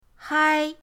hai1.mp3